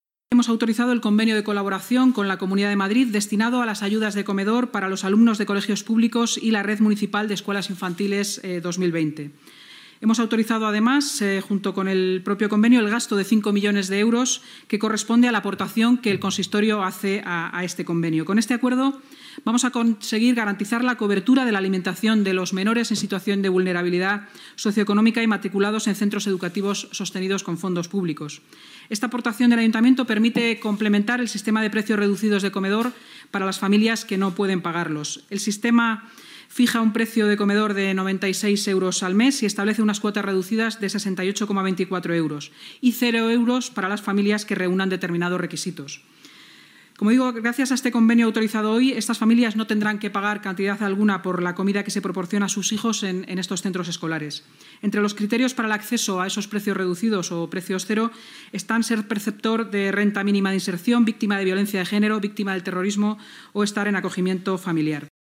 Inmaculada Sanz en la rueda de prensa tras la Junta de Gobierno de 5 de noviembre de 2020